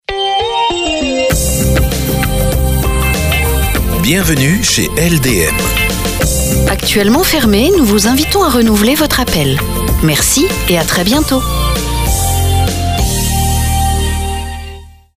Message répondeur professionnel